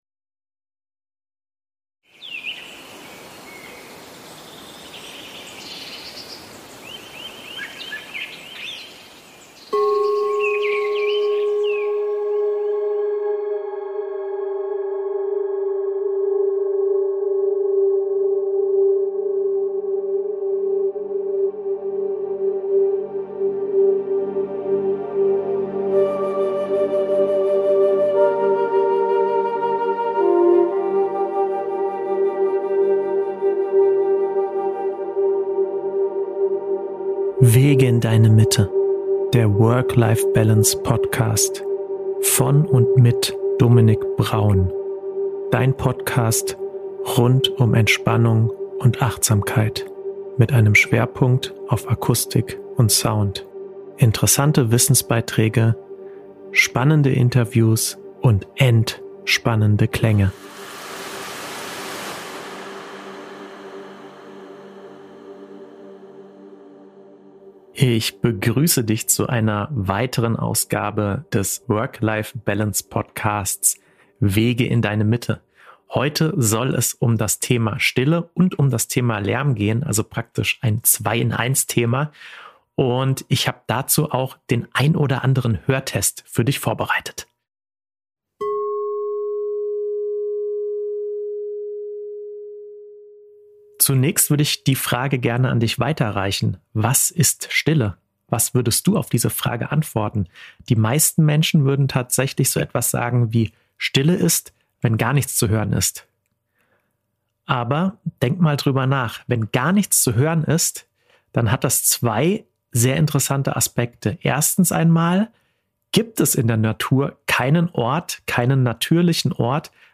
Dabei zeige ich Dir für beide Begriffe jeweils eine wissenschaftliche, eine medizinische und eine subjektive Definition. Abgerundet wird diese Podcast-Folge außerdem mit zwei Hörtests, mit denen Du herausfinden kannst, wie achtsam Du bezüglich Lärm und Störgeräuschen bist.